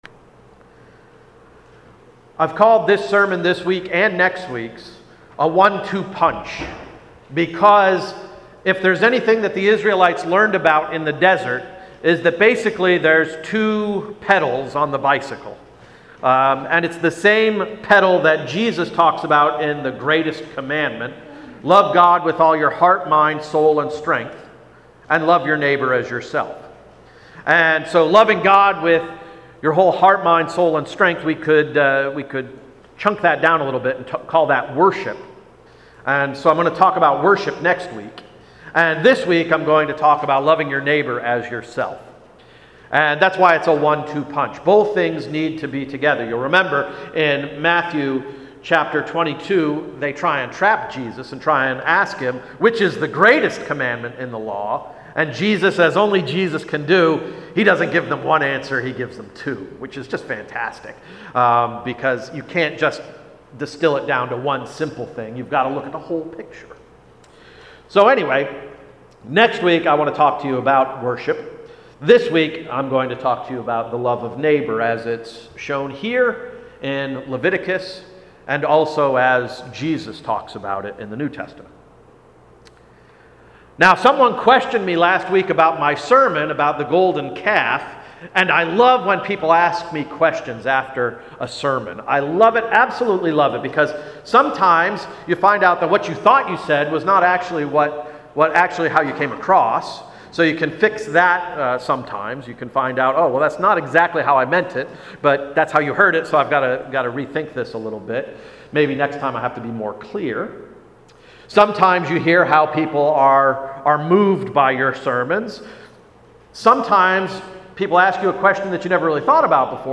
May 29, 2016 Sermon-“A One-Two Punch”